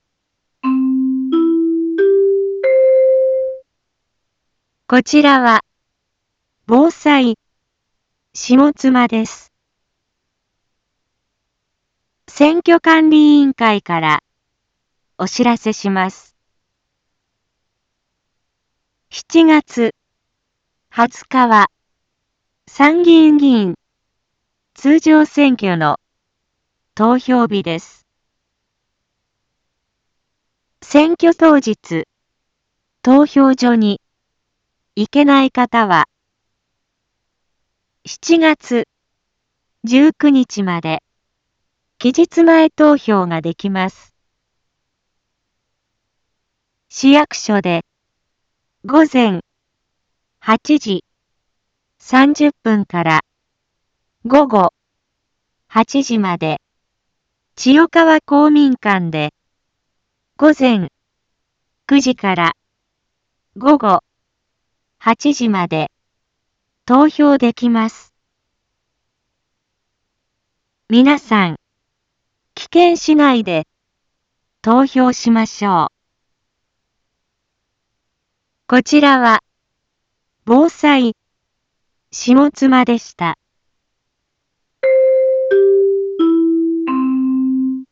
一般放送情報
Back Home 一般放送情報 音声放送 再生 一般放送情報 登録日時：2025-07-08 13:01:47 タイトル：参議院議員通常選挙の啓発（期日前投票） インフォメーション：こちらは、ぼうさいしもつまです。